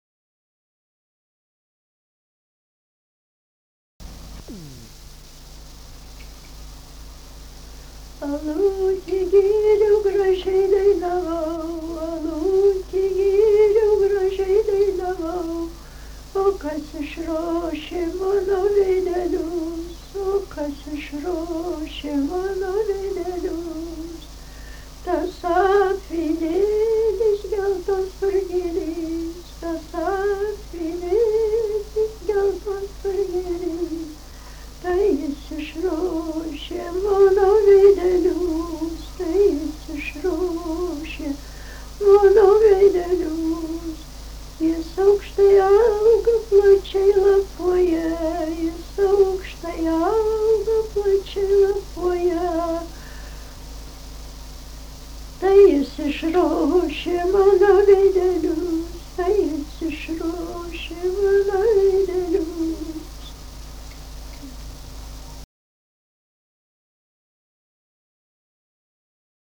daina, vaikų
Erdvinė aprėptis Šimonys
Atlikimo pubūdis vokalinis